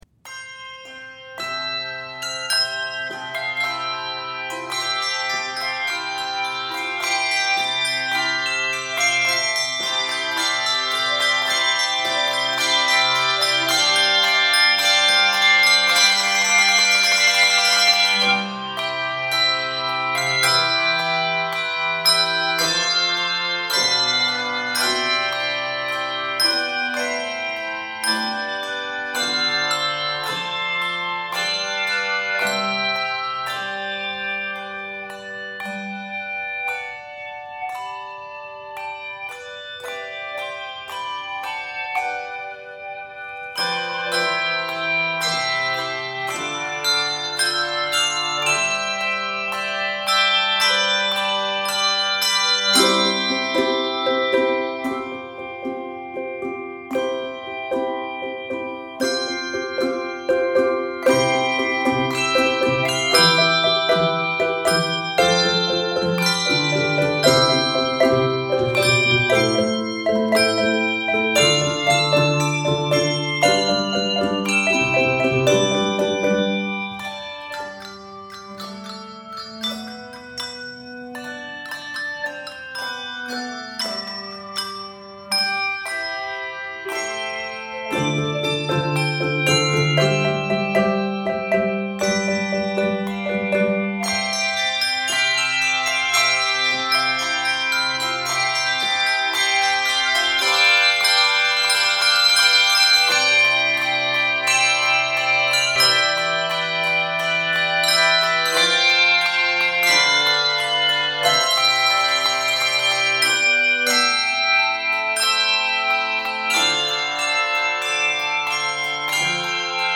handbells
Key of C Major.